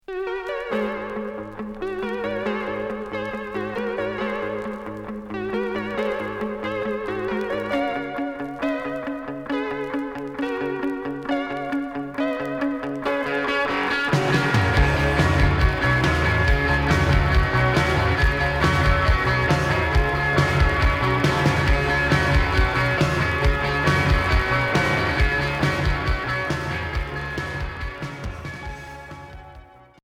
Pop cold wave